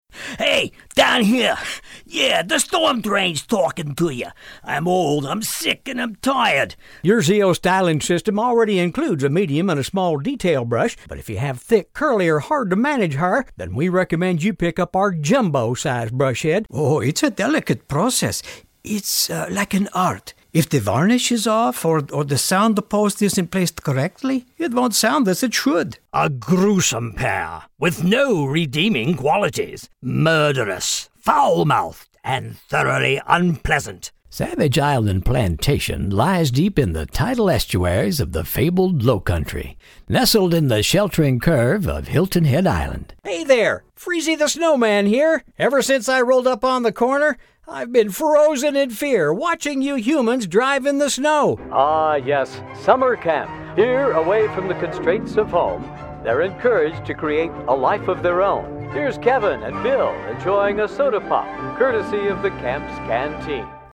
American male voice talent offering voice files for corporate & media clients. Professional studio.
Sprechprobe: Sonstiges (Muttersprache):